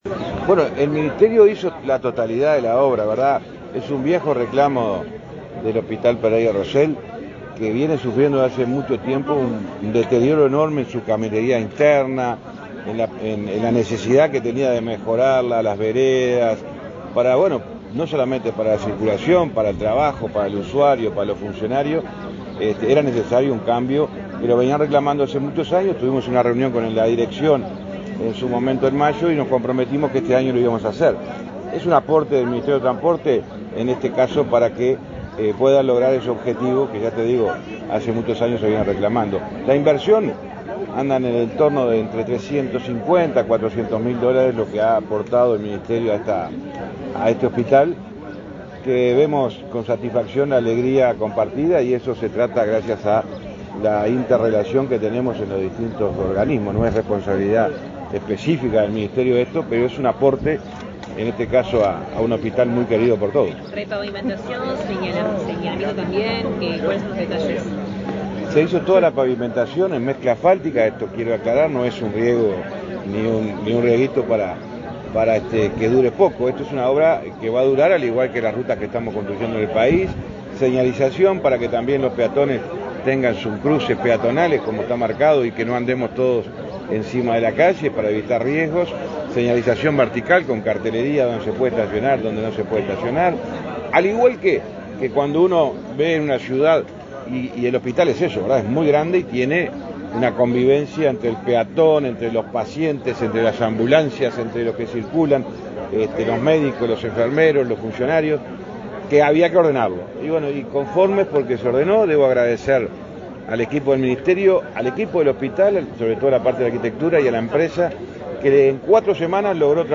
Declaraciones de prensa del ministro de Transporte, José Luis Falero
El presidente de la Administración de los Servicios de Salud del Estado (ASSE), Leonardo Cipriani; el ministro de Transporte, José Luis Falero, y la presidenta de la República en ejercicio, Beatriz Argimón, participaron, este miércoles 20, en la inauguración de la caminería interna y las veredas del hospital Pereira Rossell. Luego Falero dialogó con la prensa.